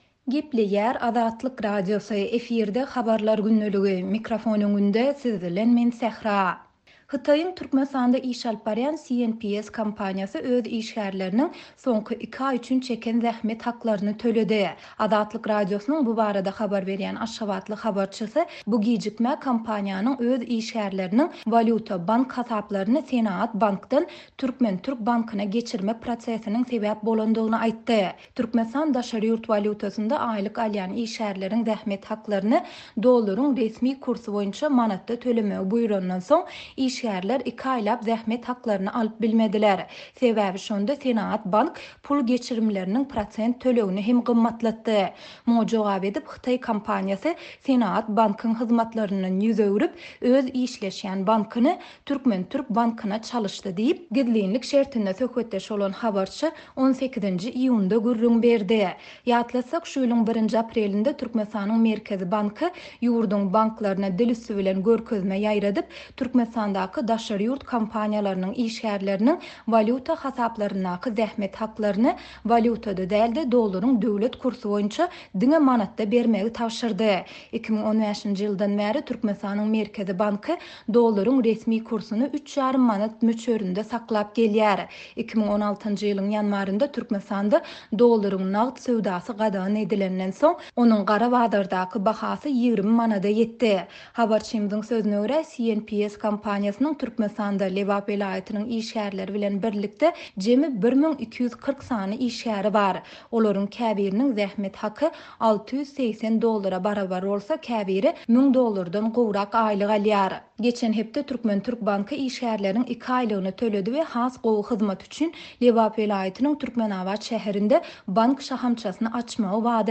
Hytaýyň Türkmenistanda iş alyp barýan CNPC ('China National Petroleum Corporation', ýagny 'Hytaýyň Milli Nebit-gaz Korporasiýasy') kompaniýasy öz işgärleriniň soňky iki aý üçin çeken zähmet haklaryny töledi. Azatlyk Radiosynyň bu barada habar berýän aşgabatly habarçysy bu gijikmä kompaniýanyň öz işgärleriniň walýuta bank hasaplaryny "Senagat" bankdan "Türkmen-türk" bankyna geçirmek prosesiniň sebäp bolandygyny aýtdy.